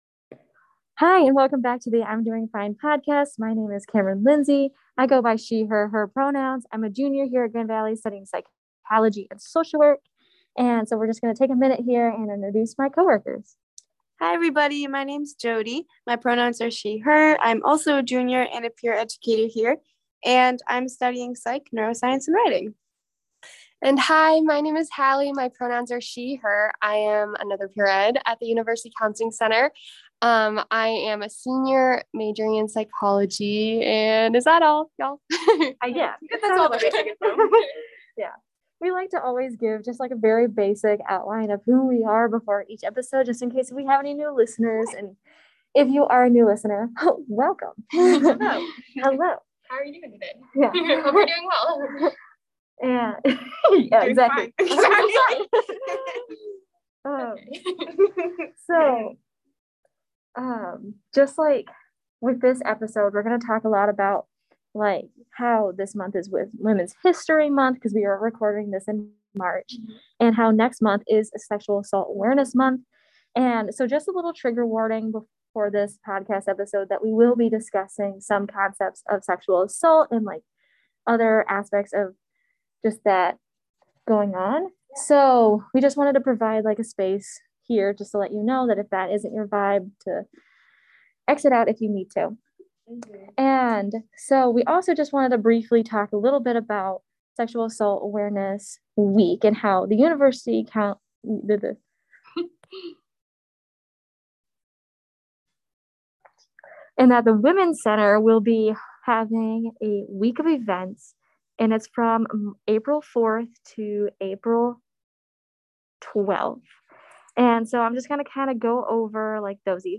Lastly, we ended the podcast with a meditation!